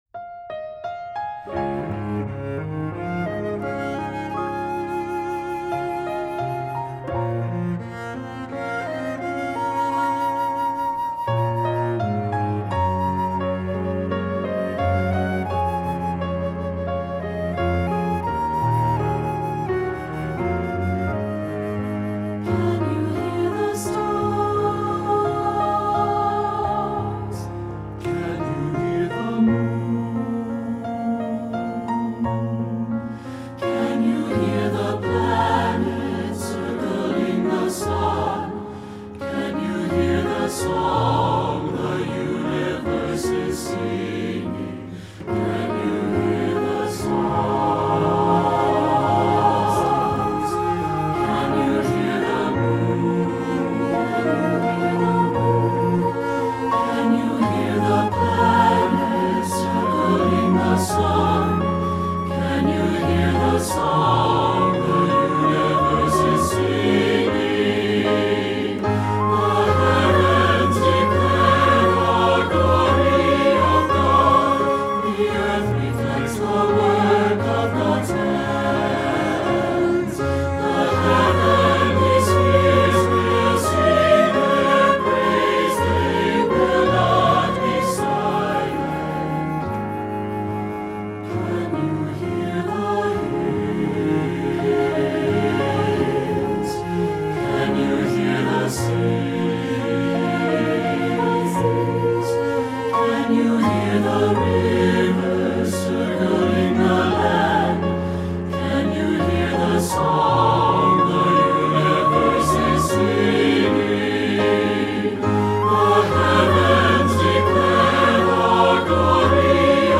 Voicing: SATB and Unison